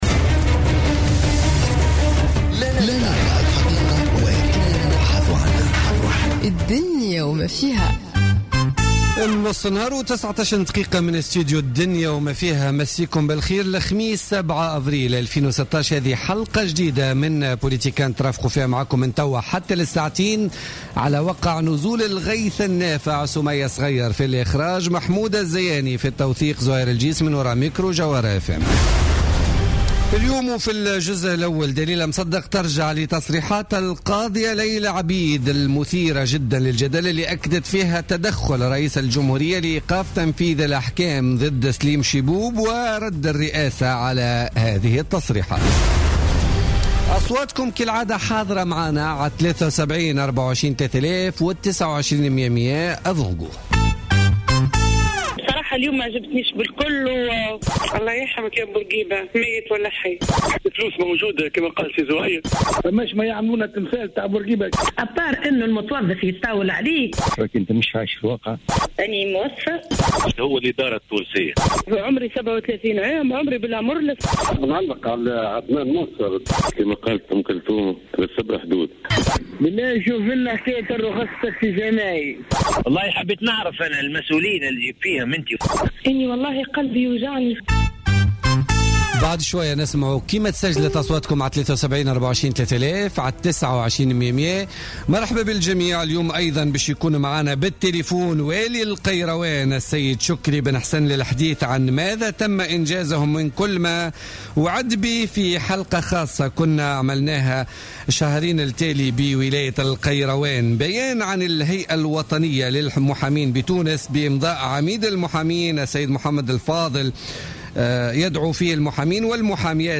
Interview exclusive de l'ambassadeur de France en Tunisie